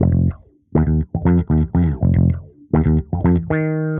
Index of /musicradar/dusty-funk-samples/Bass/120bpm